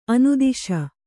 ♪ anudiśa